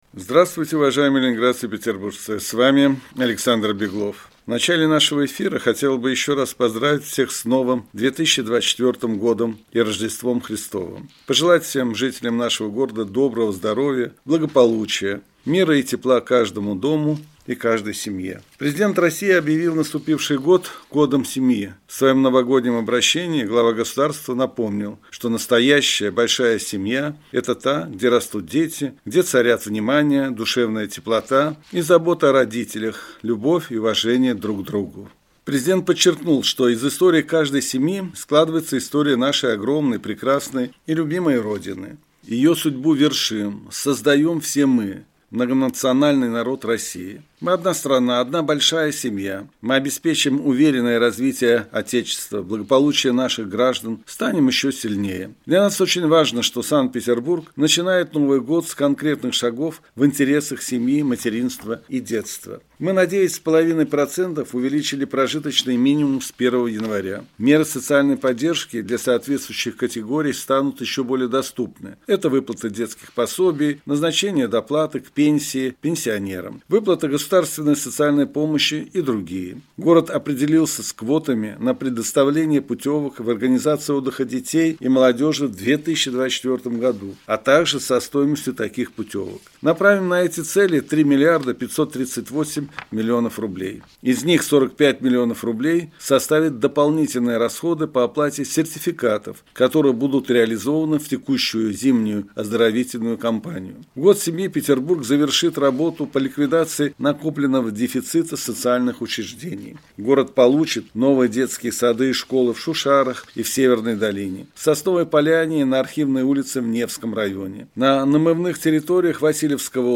Радиообращение – 9 января 2024 года